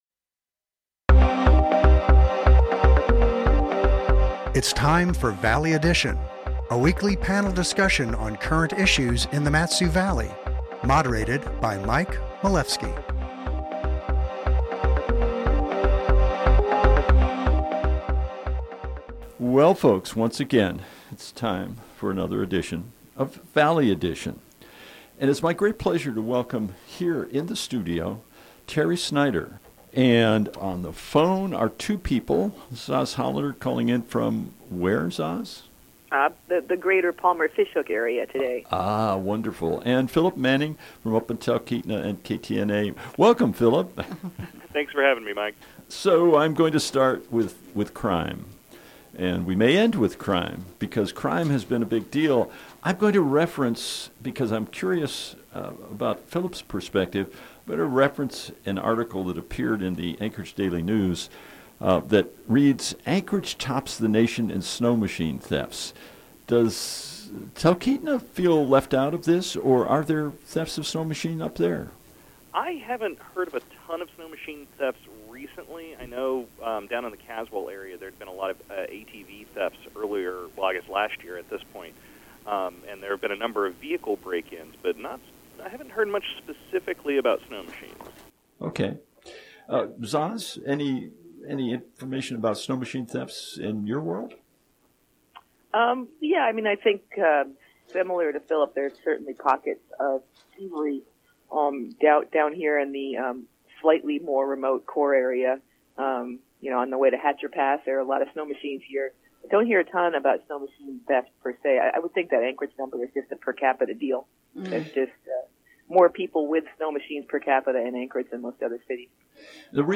moderates a panel on current issues in the Valley